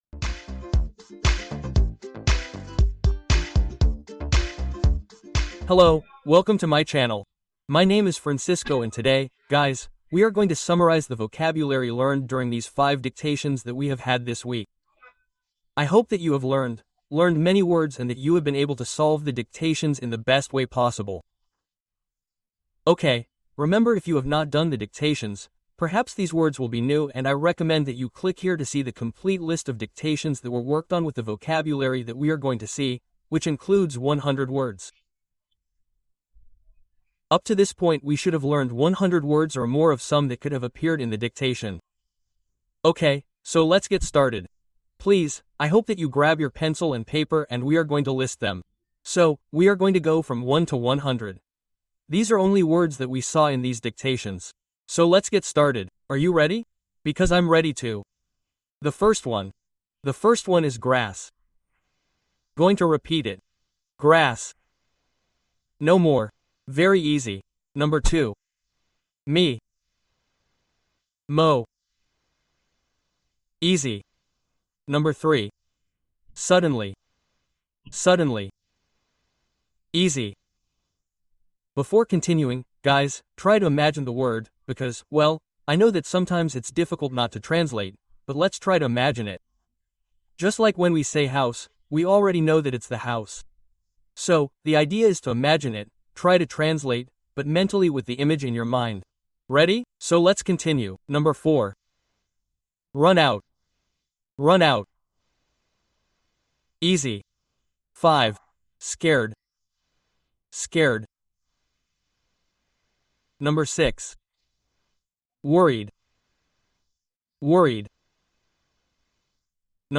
Dictado guiado de 100 palabras para expandir tu vocabulario sin esfuerzo